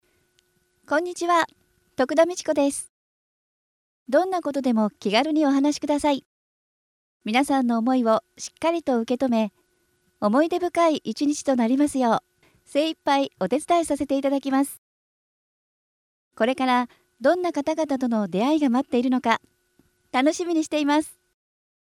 Voice
穏やかな声で、丁寧な話し方が好印象。